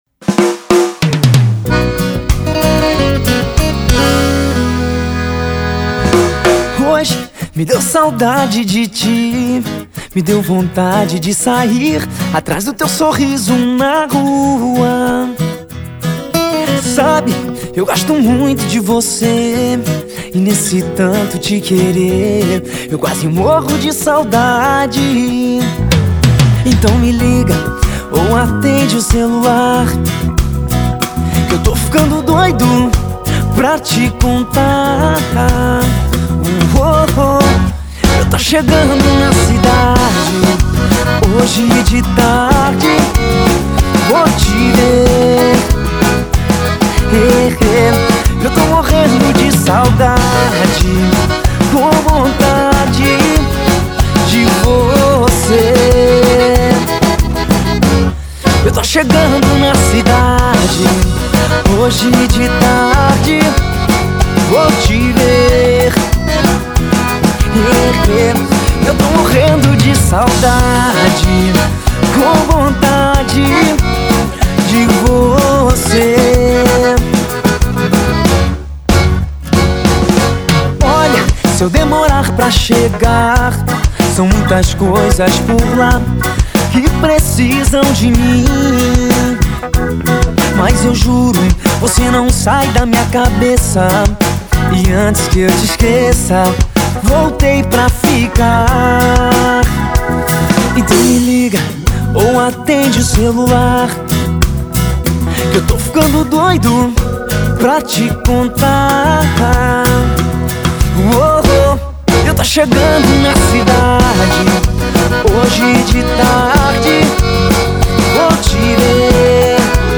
na bateria.